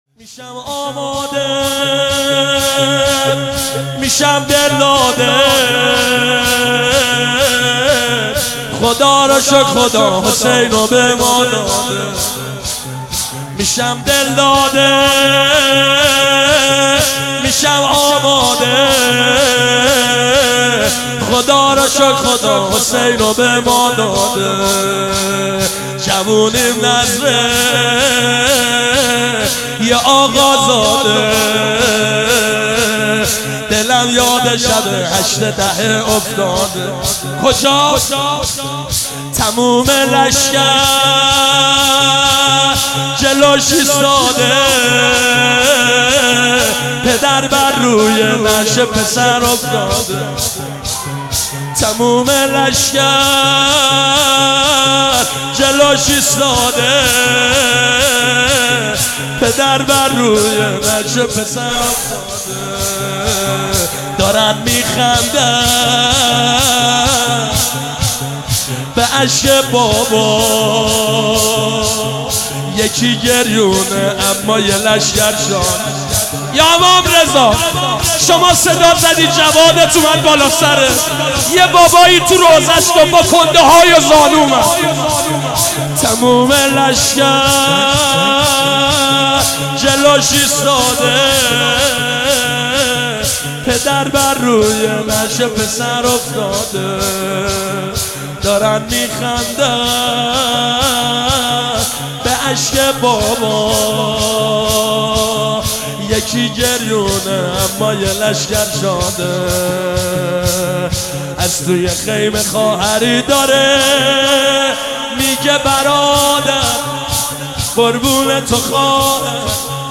قالب : شور